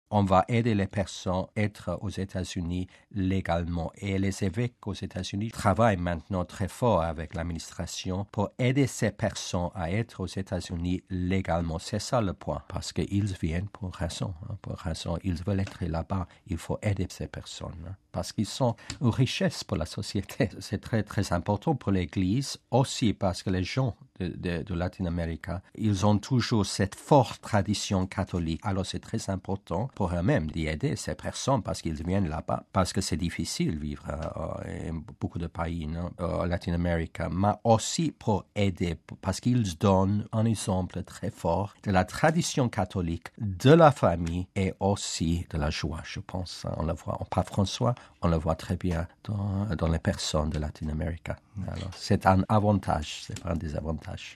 (RV) Entretien- Avec la bénédiction de l'archevêque José Horacio Gomez, un groupe de résidents de Los Angeles est à Rome depuis le 25 mars pour faire connaître le sort des immigrés illégaux vivant aux Etats-Unis.